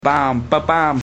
or like that :mrgreen: when using another sound scheme
bam_ba_bam.mp3